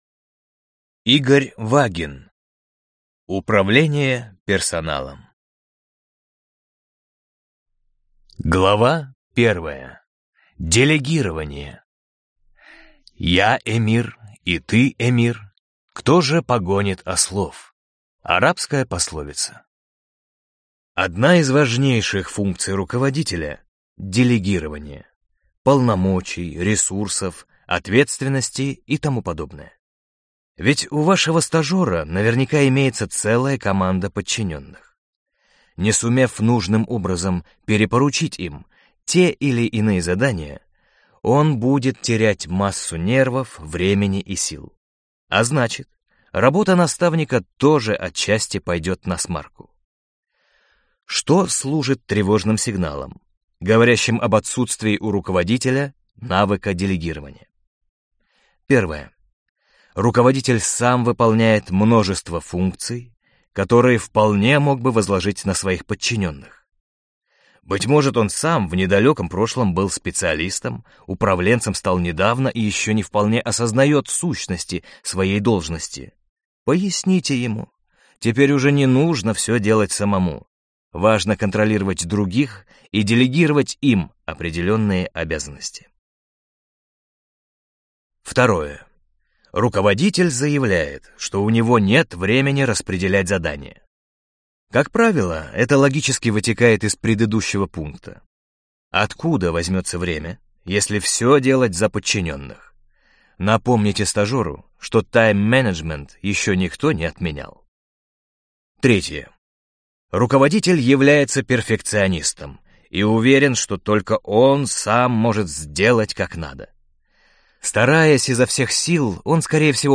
Аудиокнига Управление персоналом | Библиотека аудиокниг